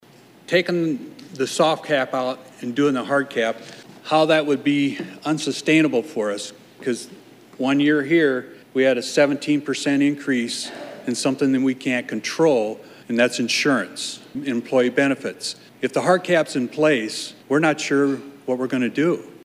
Carroll City Councilman LaVern Dirkx, who also serves on the Iowa League of Cities Executive Board, spoke before an Iowa House Committee on Tuesday as they hosted a public hearing on the Senate’s tax reform proposals.